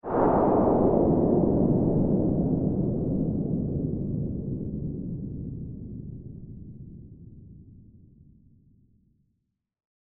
Космический корабль взмывает вдалеке